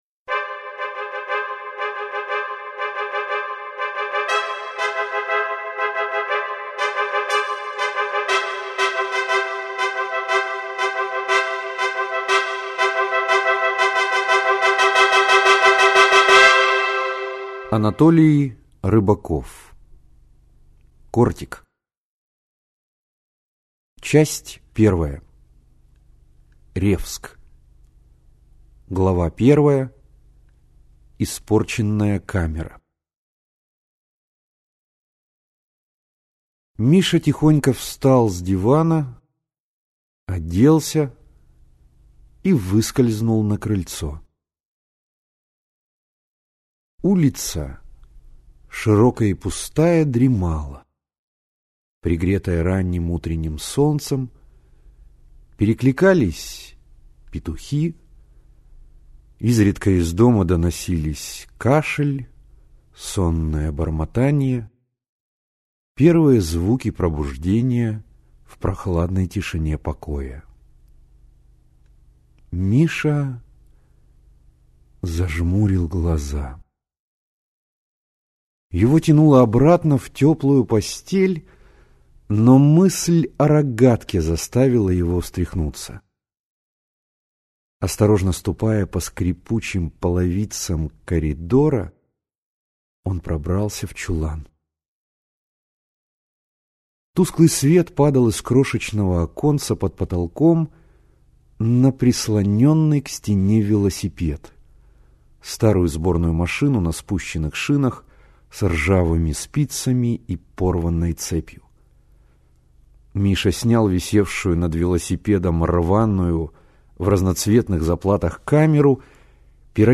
Аудиокнига Кортик | Библиотека аудиокниг